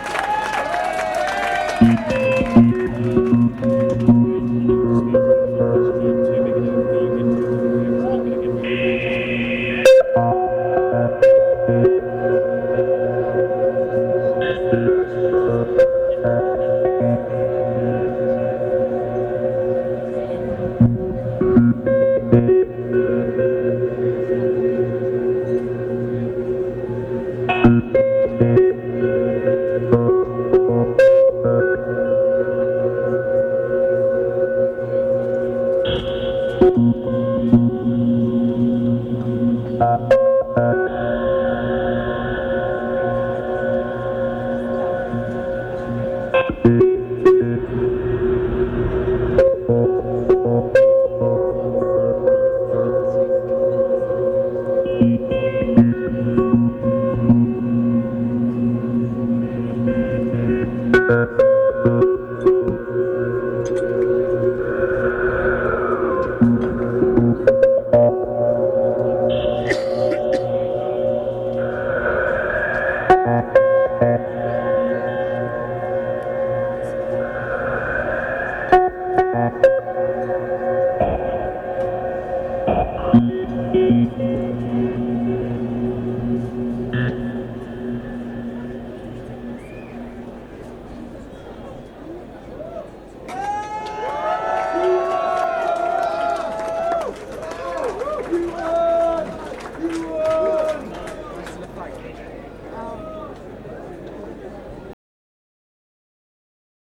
venue Camber Sands